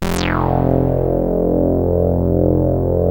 29-SAWRESWET.wav